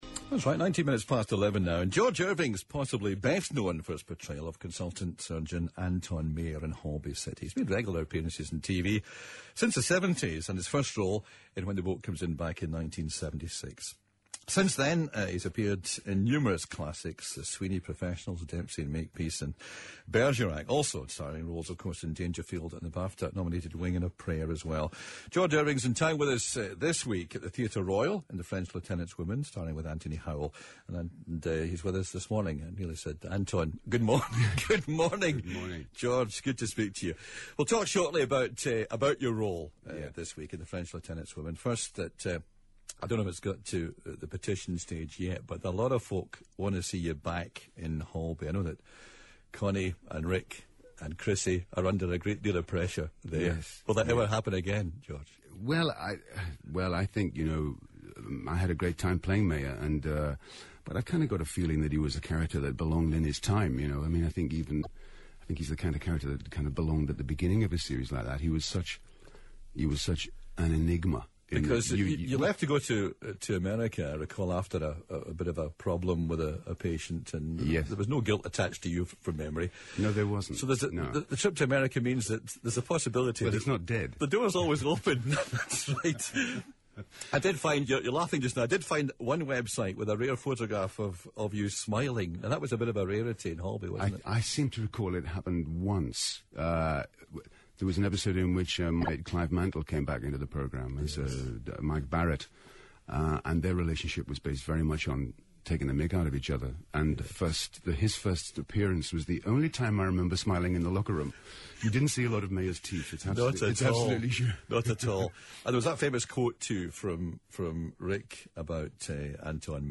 Southern Counties review Richmond Theatre review Milton Keynes Review Milton Keynes interview British Theatre Guide review Saga Radio interview one4review